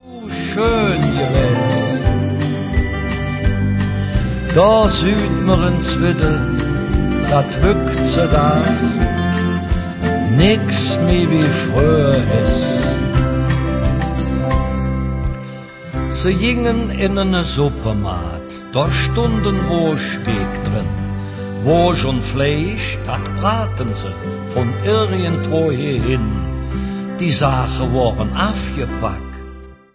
Weihnachtsleedcher und Verzällcher in Kölscher Mundart